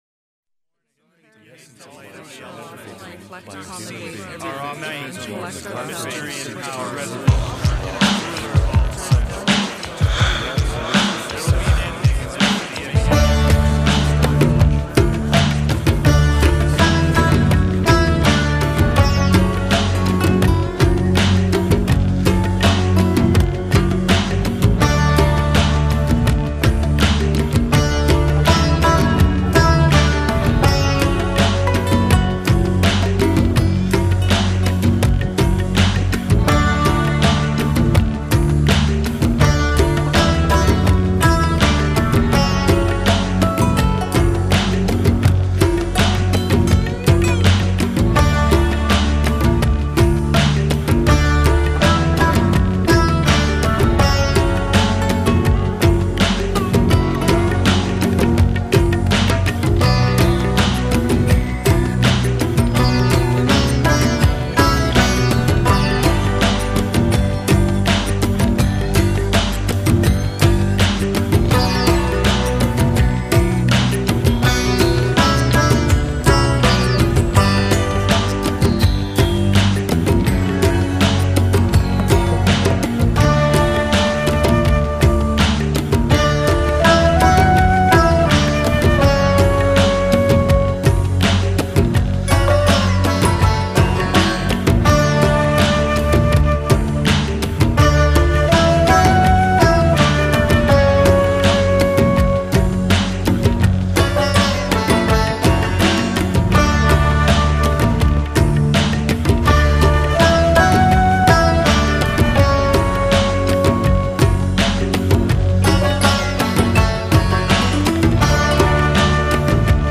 【新世纪音乐系列】
众多的乐器丰富本曲，
多元的世界风集结，
更可听见中国的独特弦琴。